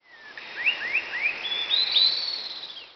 Grassbird Sound Effect ringtone free download
Sound Effects